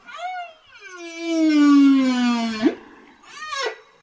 Add whale sound
sounds_whale.ogg